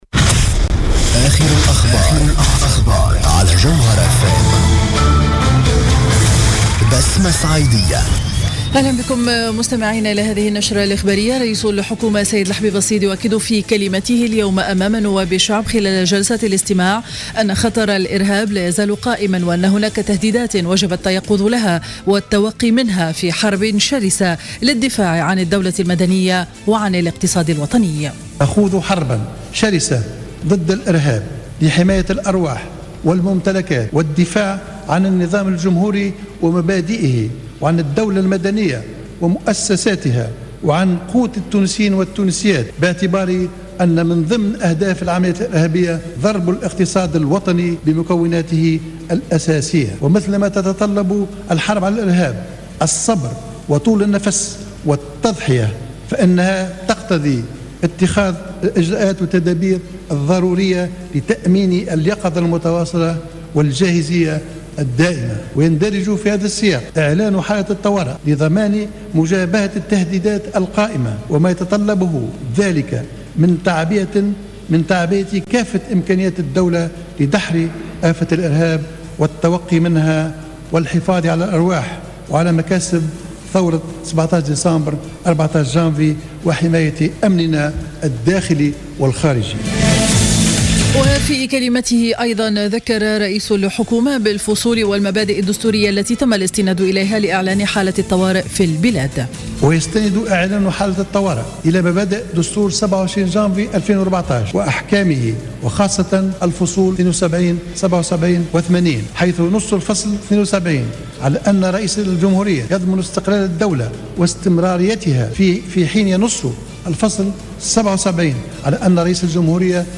نشرة أخبار منتصف النهار ليوم الإربعاء 08 جويلية 2015